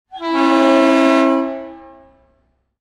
Короткий звук гудка без колокола